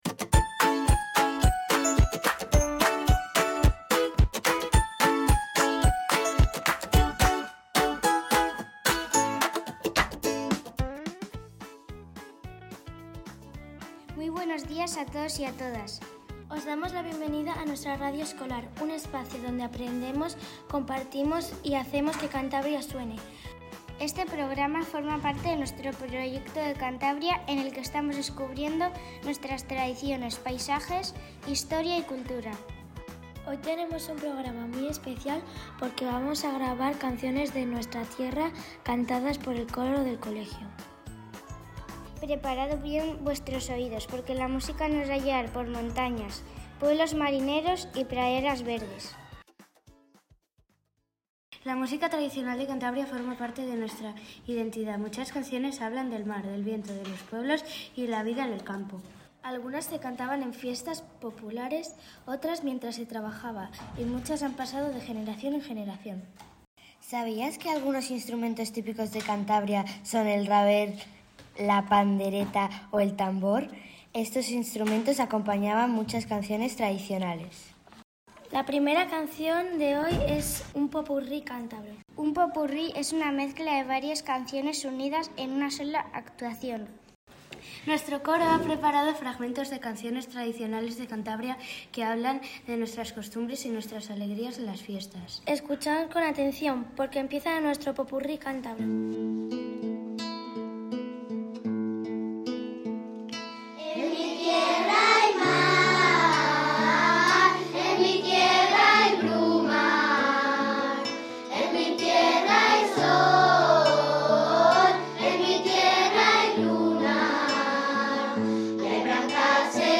Conociendo Cantabria (coro escolar)